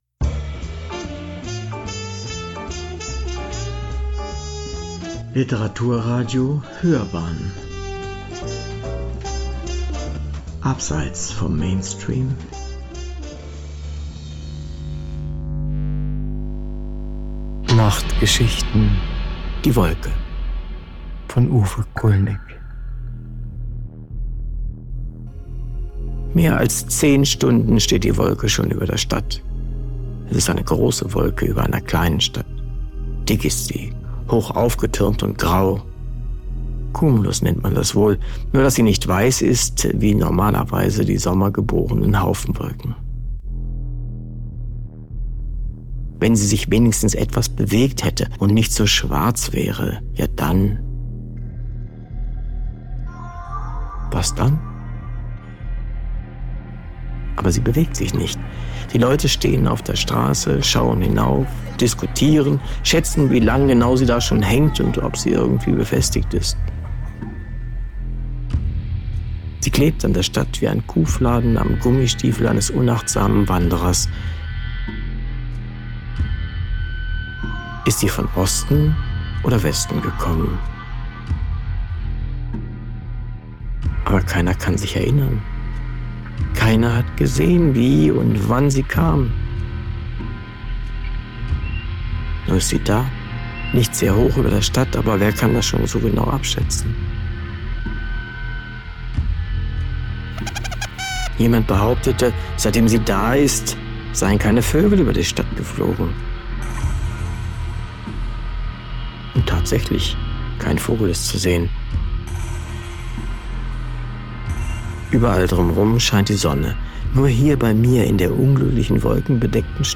Spooky …
Autor, Sprecher, Realisation: